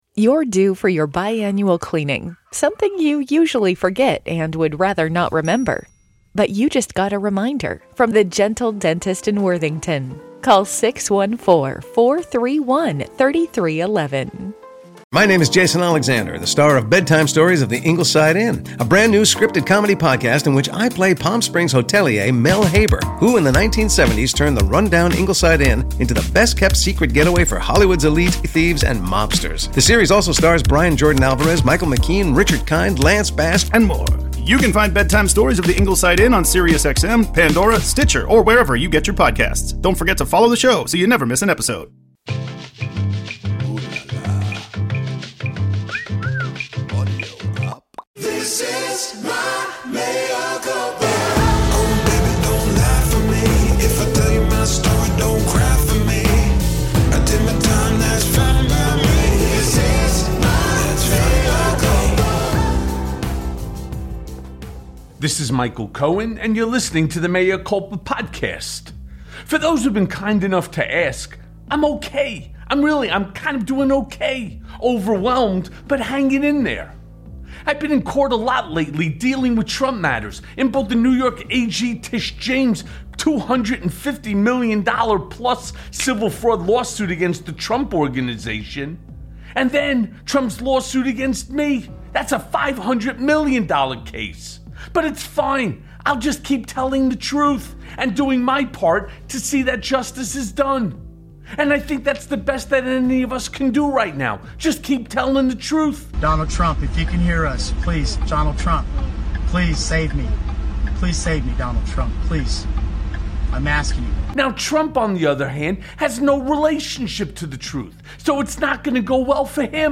Mea Culpa welcomes one of the most recognized journalists in the country, Jeffrey Toobin.